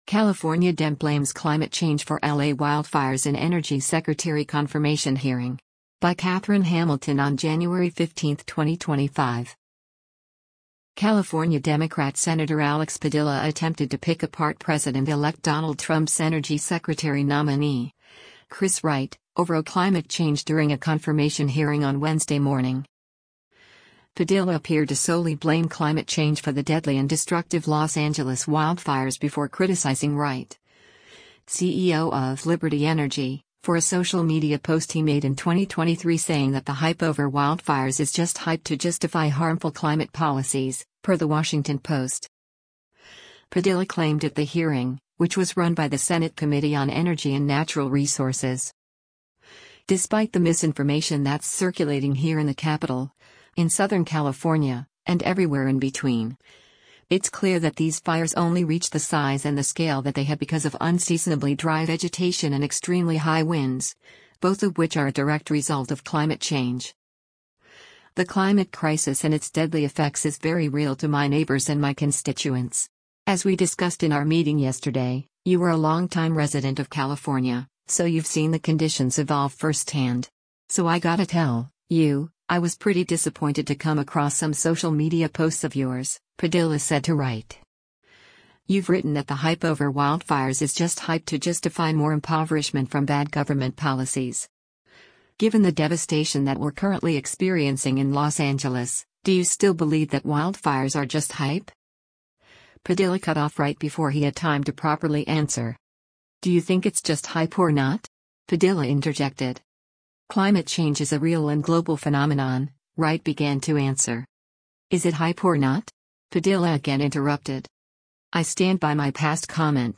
California Democrat Senator Alex Padilla attempted to pick apart President-elect Donald Trump’s Energy Secretary nominee, Chris Wright, over climate change during a confirmation hearing on Wednesday morning.
Padilla cut off Wright before he had time to properly answer.
During the hearing, left-wing climate change protesters interrupted proceedings at least five times.